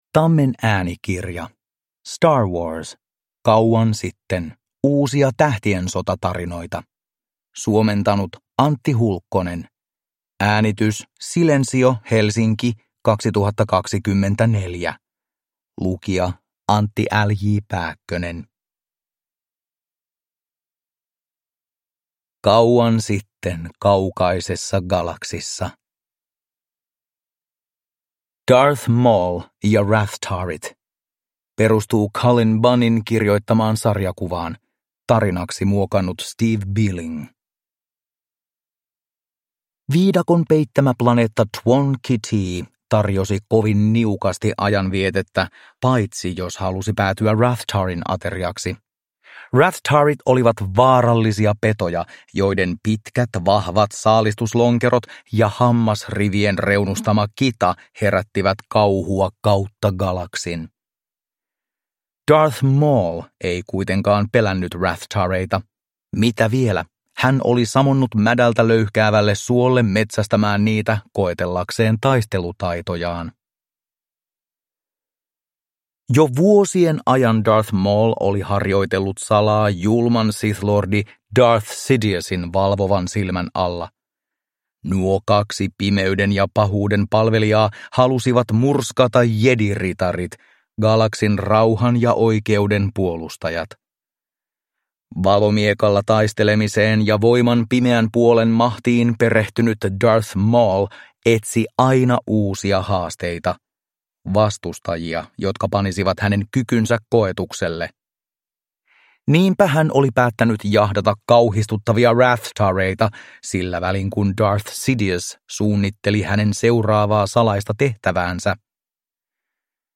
Star Wars. Kauan sitten… – Ljudbok